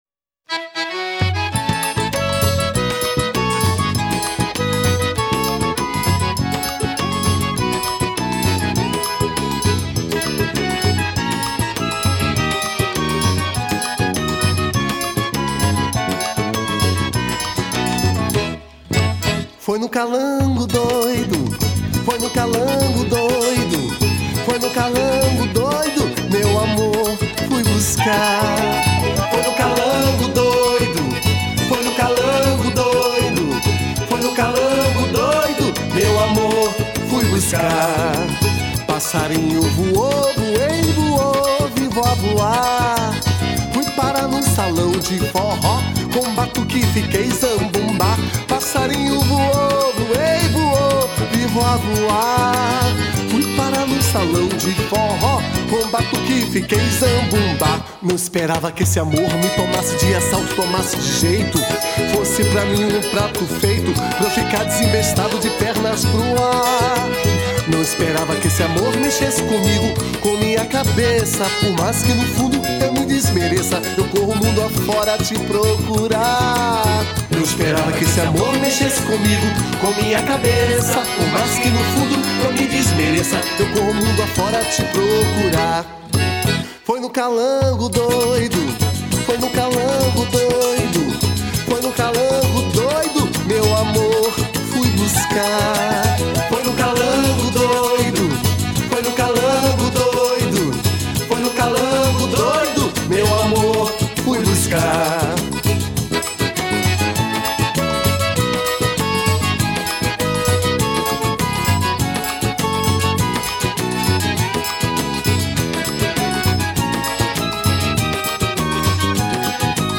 Quadrilha Forro Nordeste Festa Junina